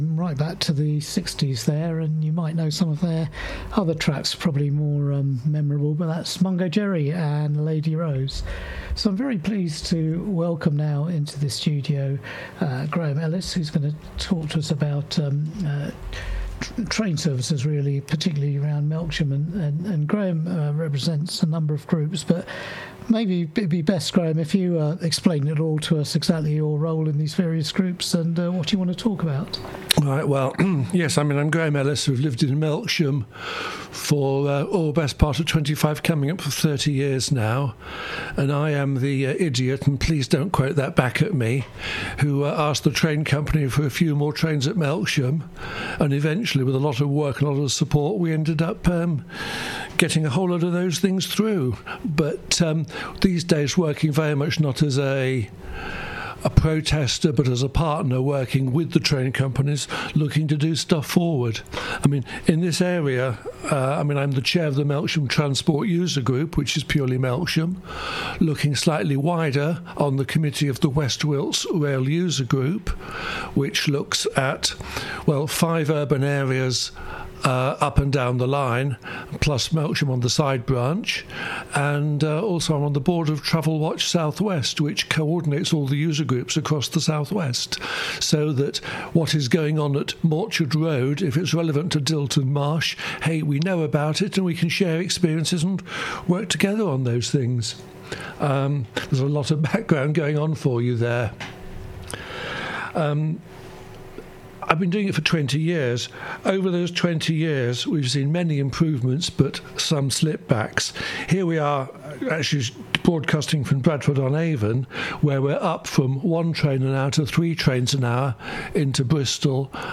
From my blog I did a rail interview for West Wilts Radio on 13th January 2026. A wonderful opportunity, over some 20 minutes, introduce the listeners to: * what has been achieved, * where challenges remain, * why some things are as they are, and * to look forward for a year and perhaps for a decade.